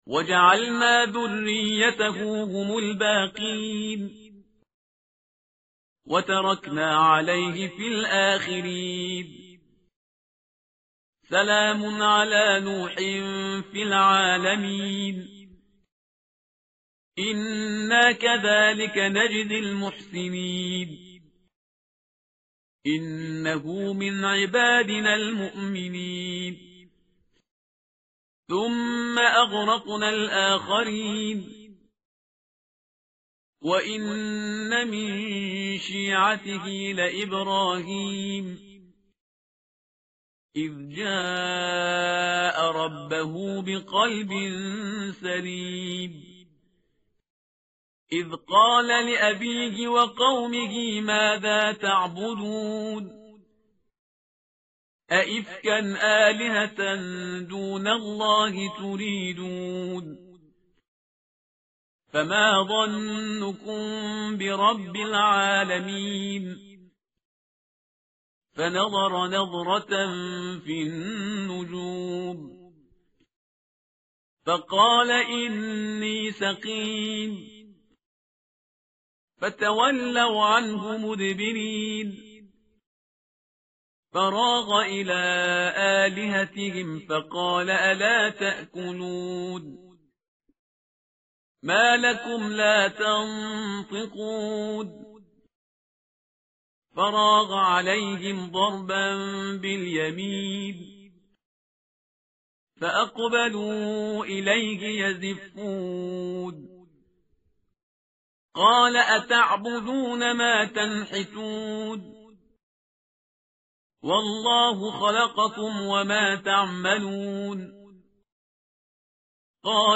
tartil_parhizgar_page_449.mp3